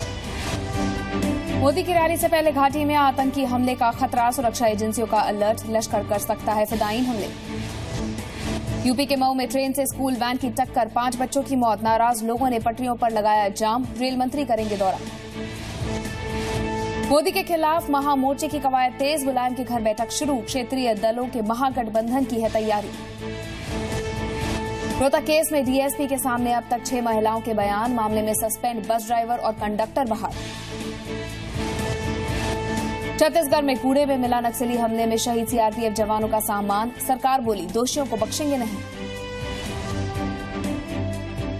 Top news headlines at 1:30 pm